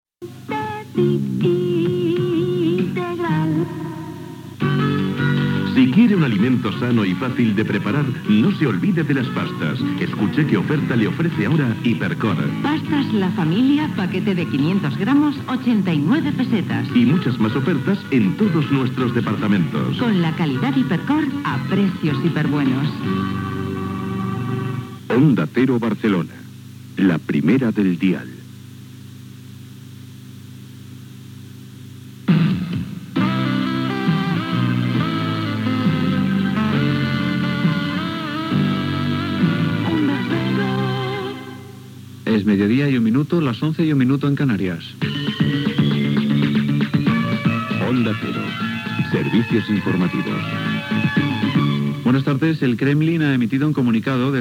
Publicitat, indicatiu de l'emissora i de la cadena, hora i careta d'"Onda Cero Sevicios Informativos".